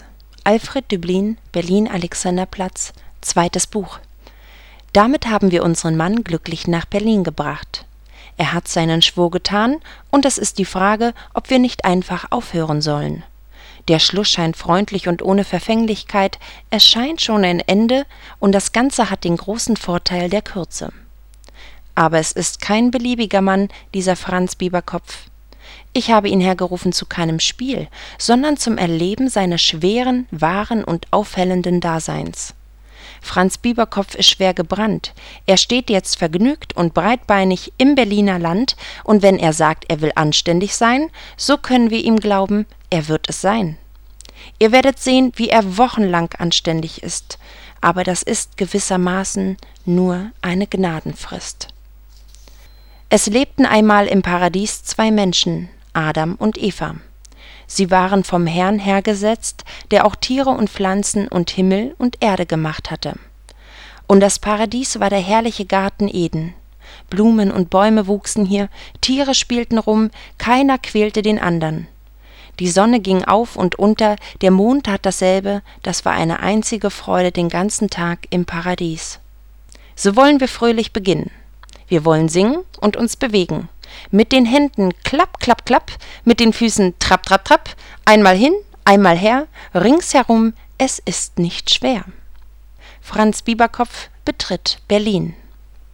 Off-Sprecherin für diverse Beiträge und Monatsrückblicke der Medienwelt, Lesungen, Synchronsprecherin, Rezitationen
Sprechprobe: Werbung (Muttersprache):